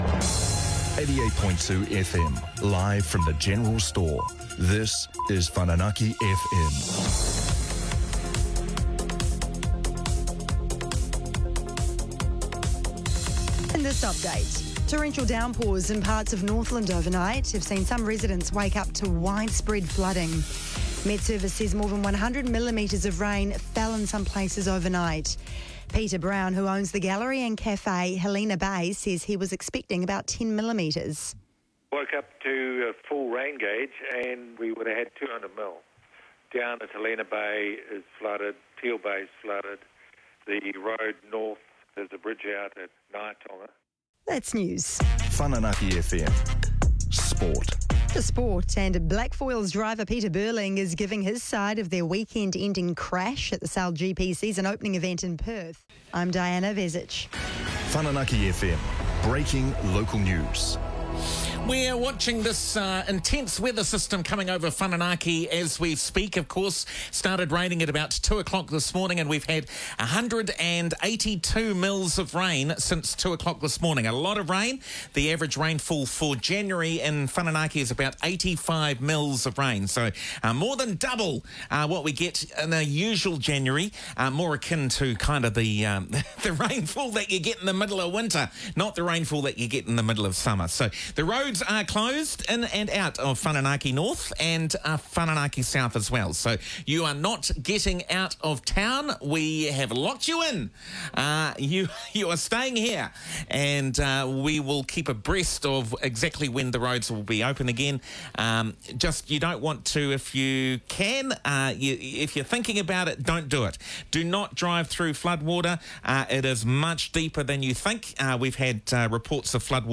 10am top-of-hour NZME affiliate news and sport, followed immediately by a local breaking news sting.
Overall, a great example of what “live, local radio” actually sounds like - imperfect, human, community-focused and invaluable during significant local events.